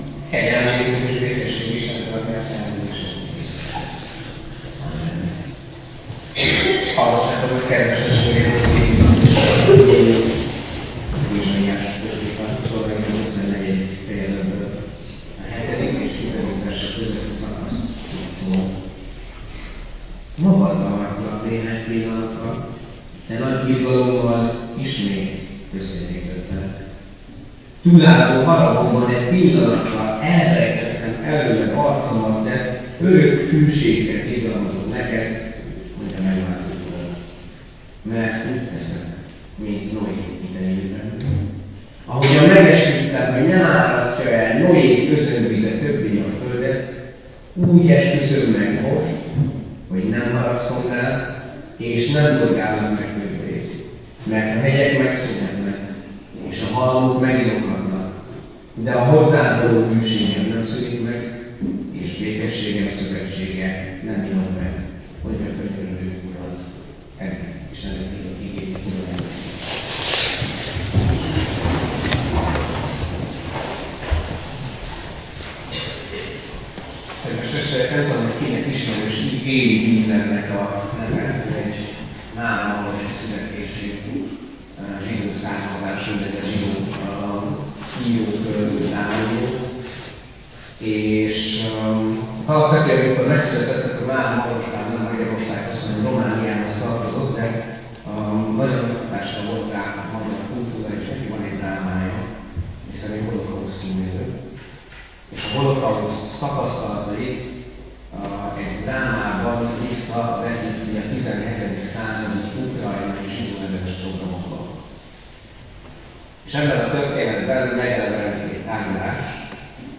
(Ézs 66,10) - (Megint elnézést, most a hangminőség miatt.)